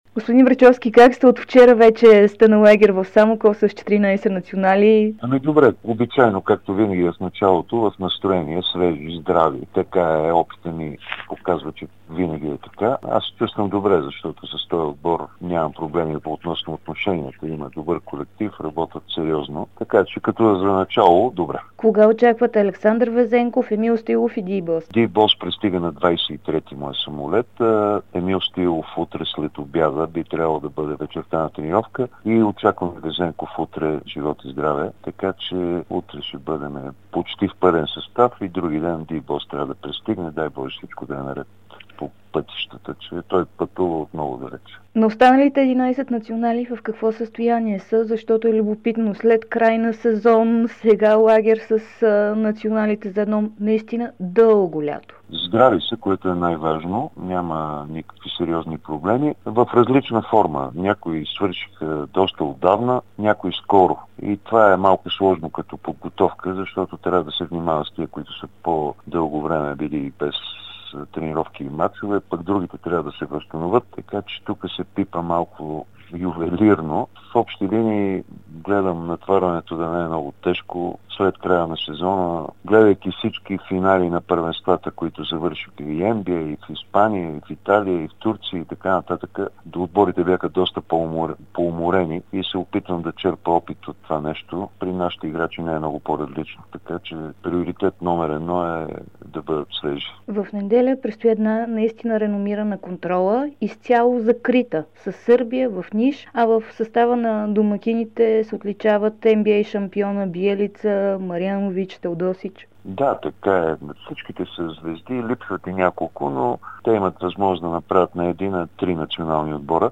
Националният селекционер Росен Барчовски даде специално интервю за Дарик радио и dsport.